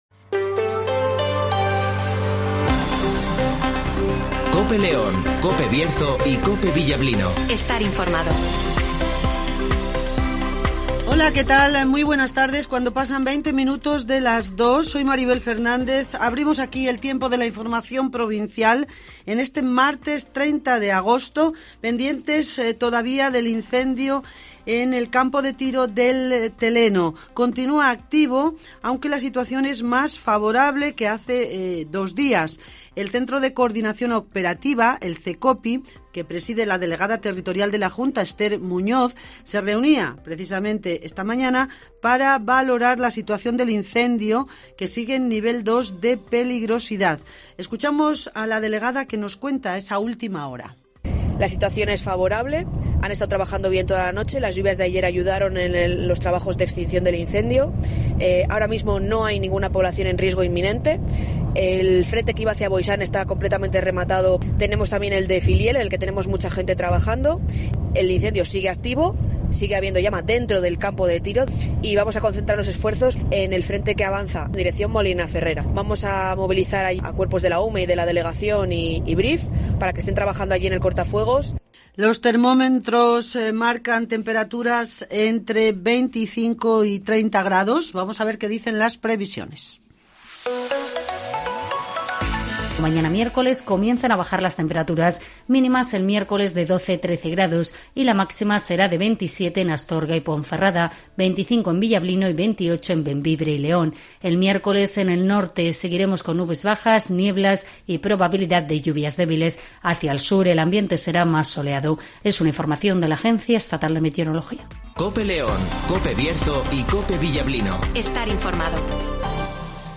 - Ester Muñoz ( Delegada Territorial de la JCyL )
- Nicanor Sen ( Diputado de Turismo )